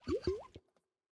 sounds / mob / axolotl / idle2.ogg